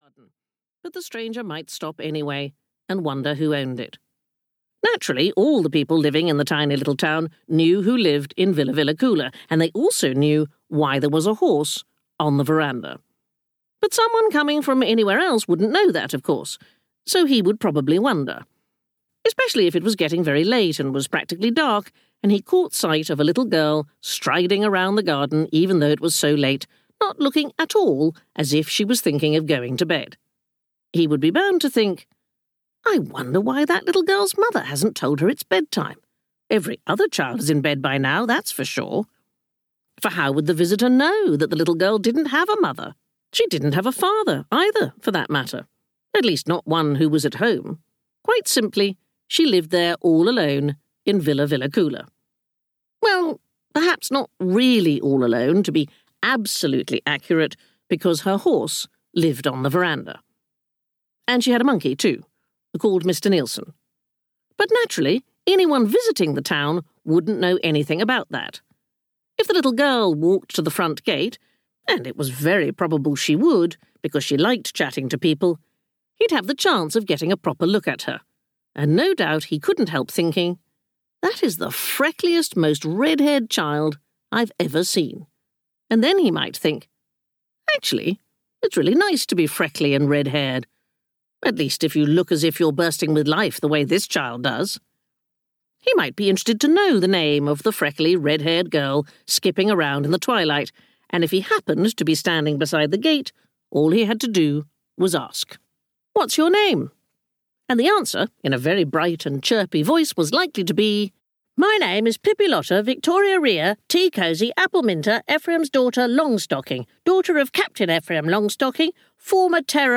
Pippi Longstocking Goes Aboard (EN) audiokniha
Ukázka z knihy
• InterpretSandi Toksvig